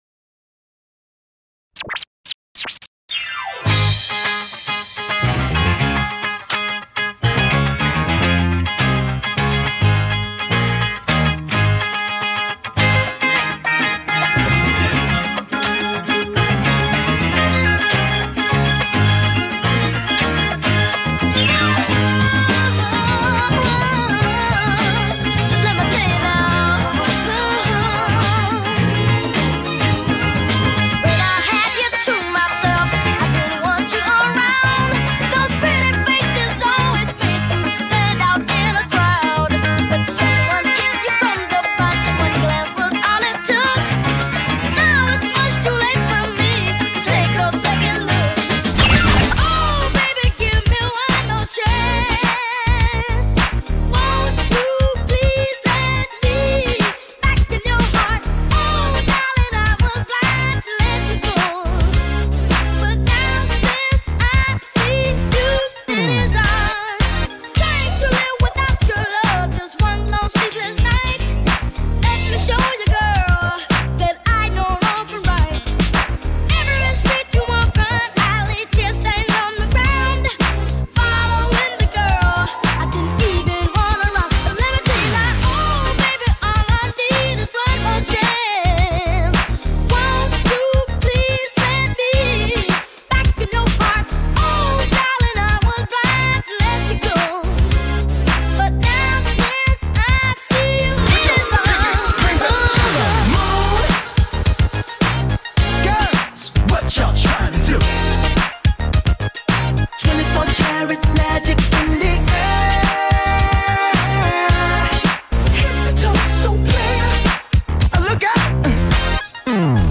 Mix: Live Wedding Mix
A live wedding mix